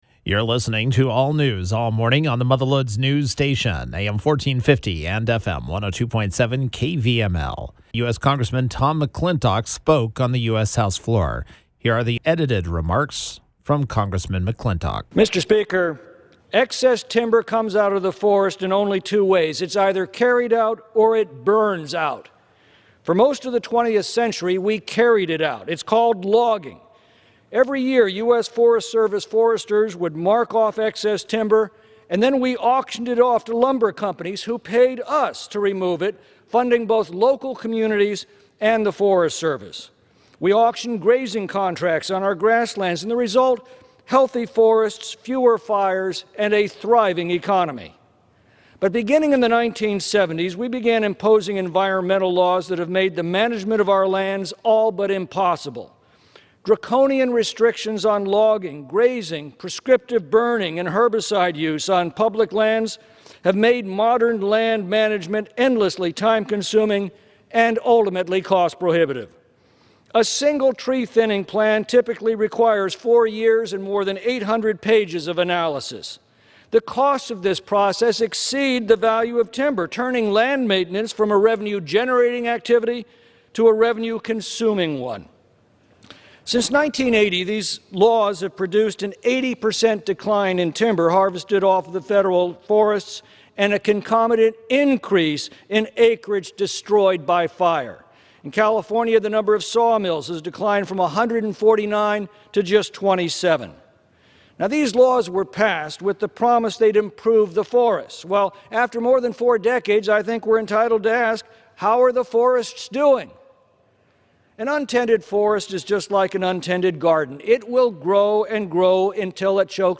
Washington, DC — Congressman Tom McClintock spoke on the US House floor about the wildfires burning across California.